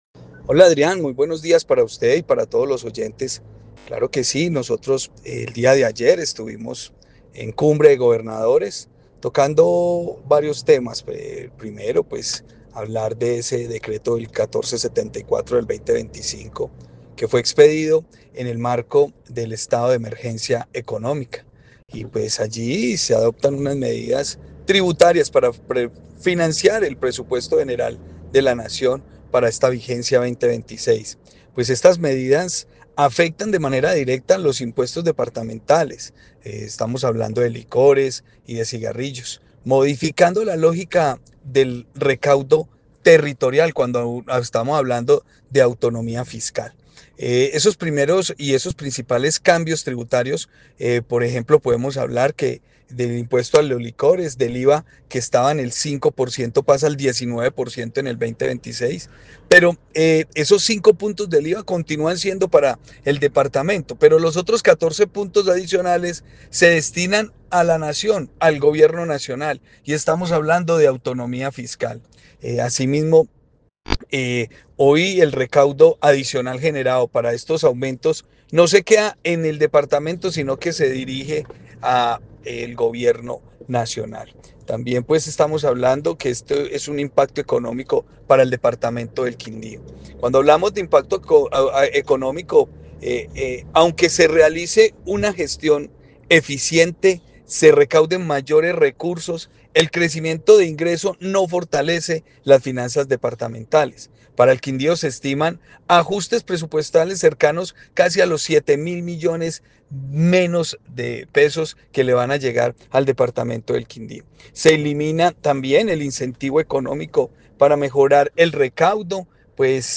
Juan Miguel Galvis, gobernador del Quindío
En diálogo con 6AM/W de Caracol Radio Armenia el mandatario departamental expresó “nosotros estuvimos en cumbre de gobernadores tocando varios temas, primero pues hablar de ese decreto del 1474 del 2025 que fue expedido en el marco del estado de emergencia económica y pues allí se adoptan unas medidas tributarias para financiar el presupuesto general de la nación para esta vigencia 2026.